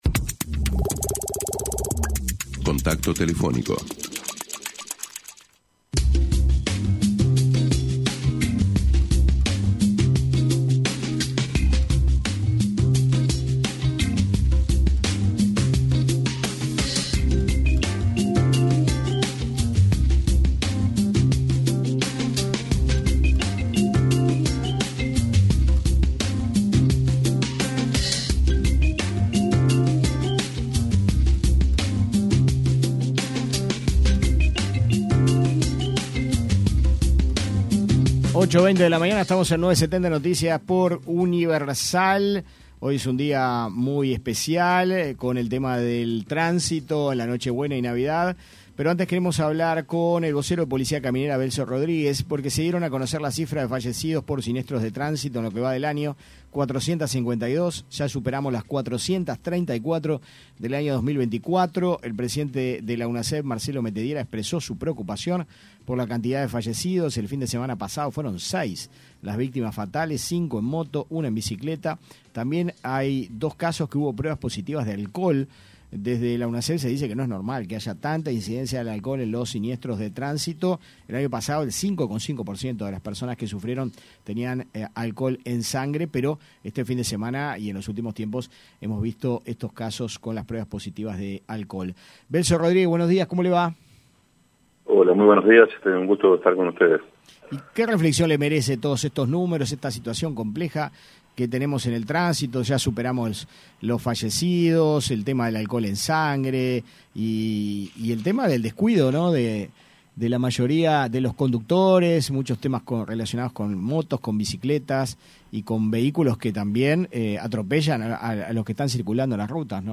entrevista con 970 Noticias